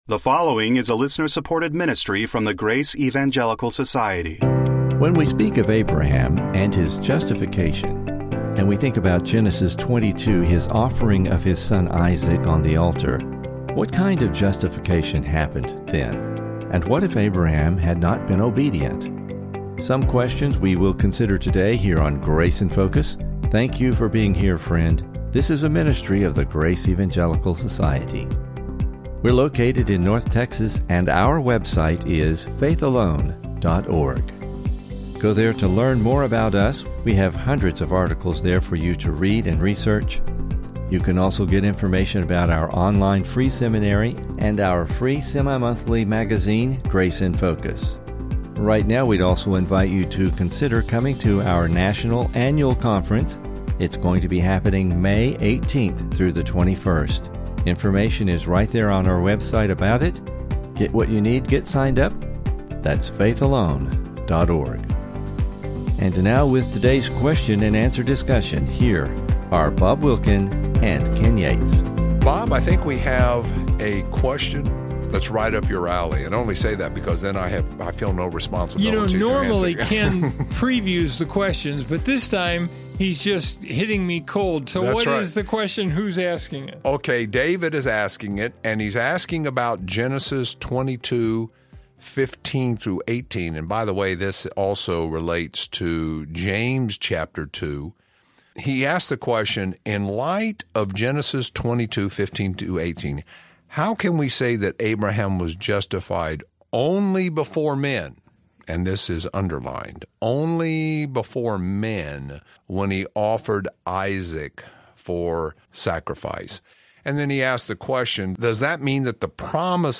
question and answer discussion